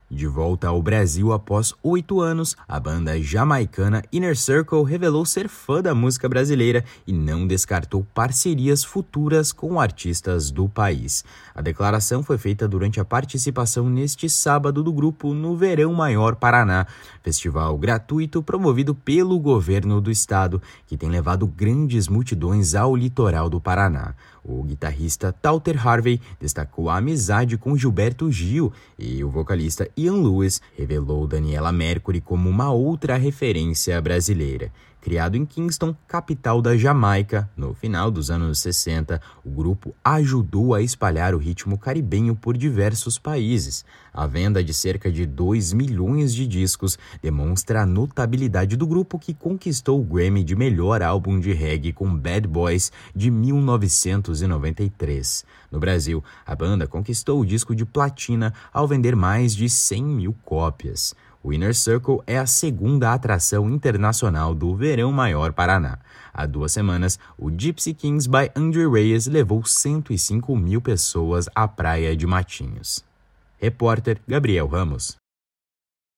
A declaração foi feita durante a participação neste sábado do grupo no Verão Maior Paraná, festival gratuito promovido pelo Governo do Paraná, que tem levado grandes multidões ao Litoral do Estado. O guitarrista Touter Harvey destacou a amizade com Gilberto Gil, e já o vocalista Ian Lewis revelou Daniela Mercury como uma outra referência brasileira.